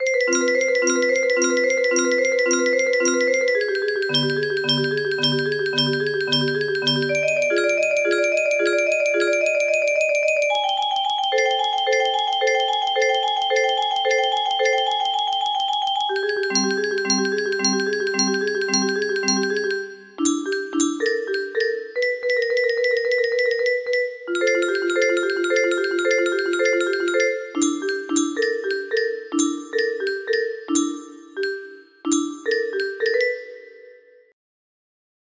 Solarly Day music box melody